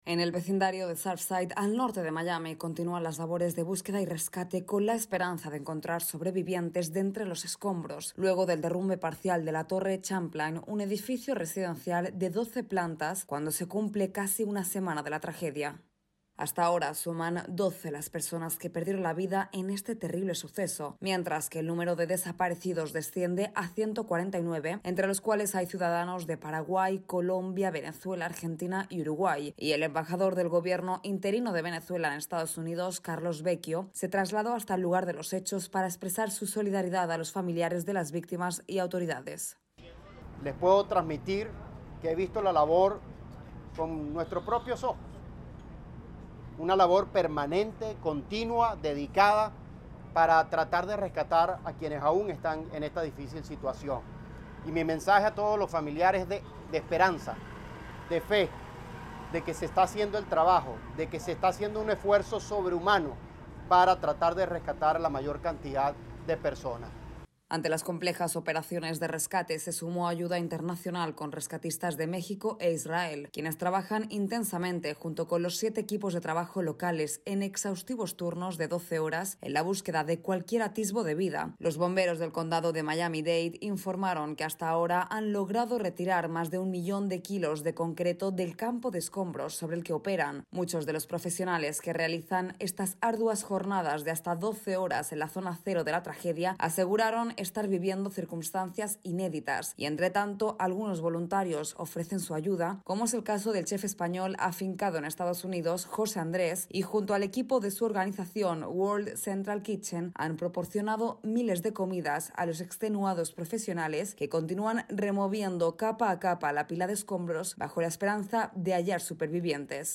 Los equipos de rescate trabajan en exhaustivos turnos de doce horas en la zona cero del derrumbe parcial de un edificio residencial al norte de Miami. Informa